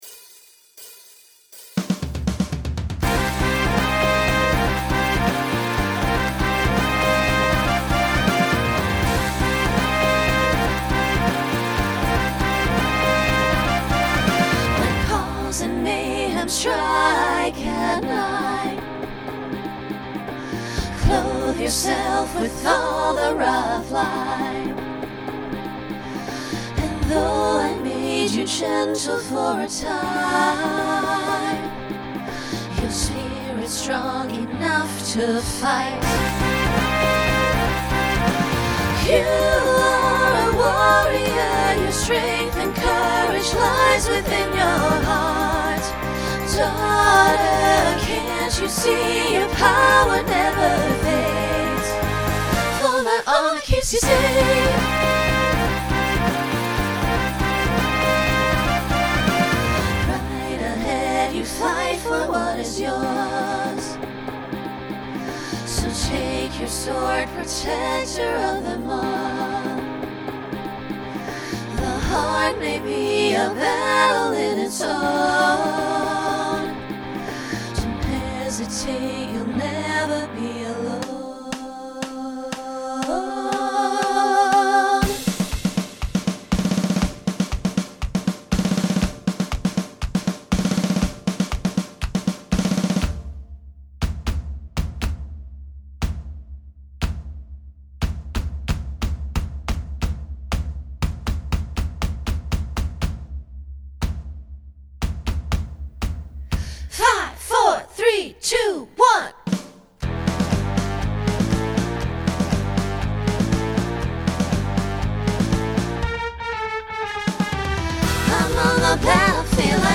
Genre Folk , Pop/Dance
Voicing SSA